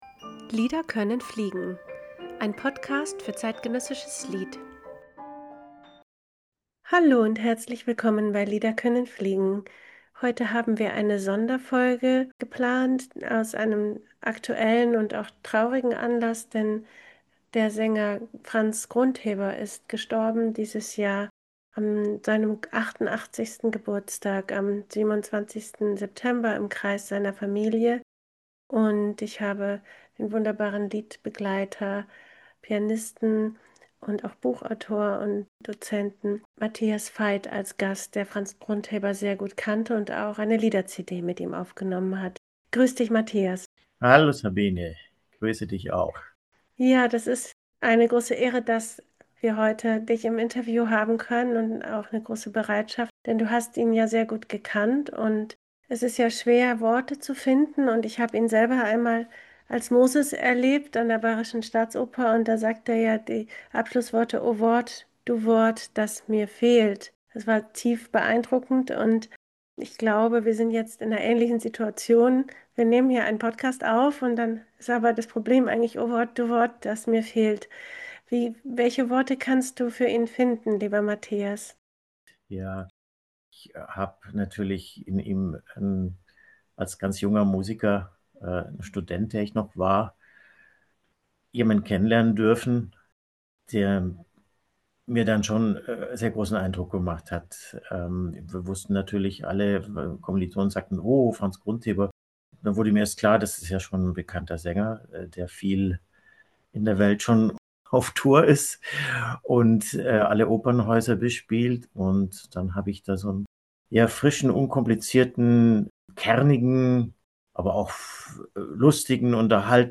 erzählt der Liedpianist